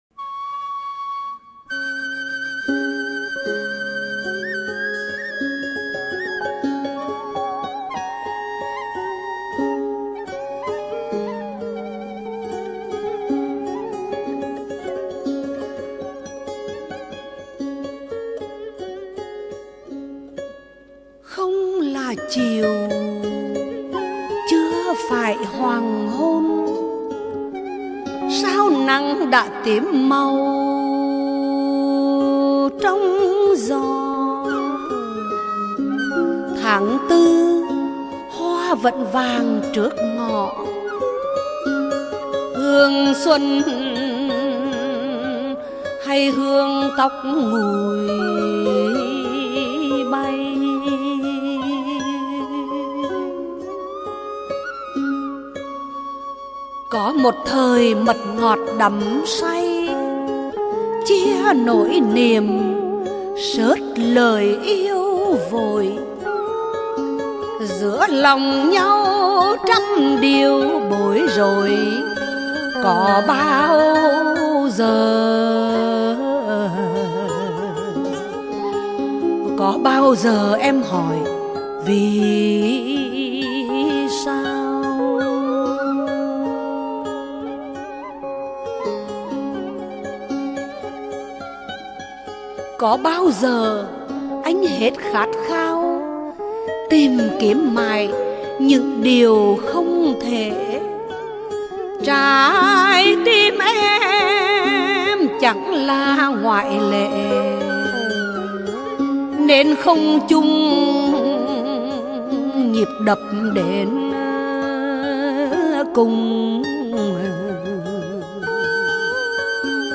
Ngâm Thơ